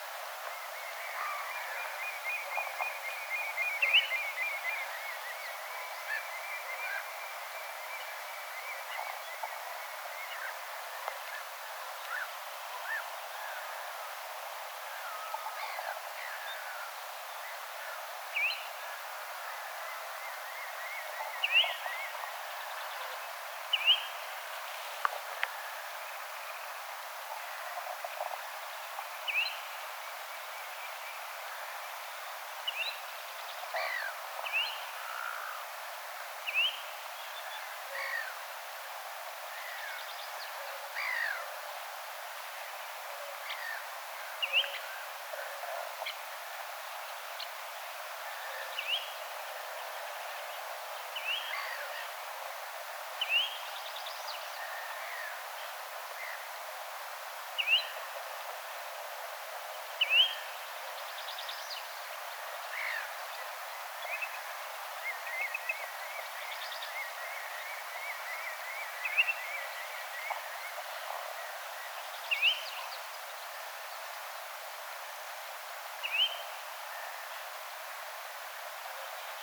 kivatviklon ääntelyä
kivat_viklon_aantelya.mp3